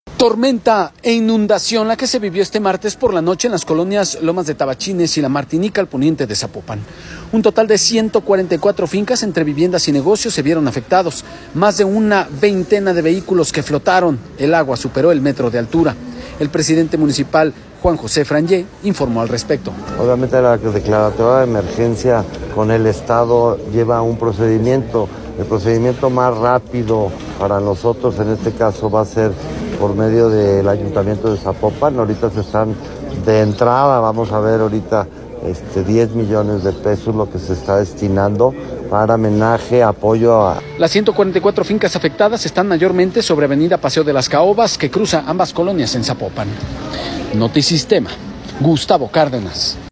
Un total de 144 fincas, entre viviendas y negocios, se vieron afectados; más de una veintena de vehículos flotaron, el agua superó el metro de altura. El presidente municipal, Juan José Frangie, informó al respecto.